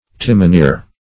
timoneer - definition of timoneer - synonyms, pronunciation, spelling from Free Dictionary
Search Result for " timoneer" : The Collaborative International Dictionary of English v.0.48: Timoneer \Tim`o*neer"\, n. [F. timonier, fr. timon a helm, fr. L. temo, -onis, a pole.]
timoneer.mp3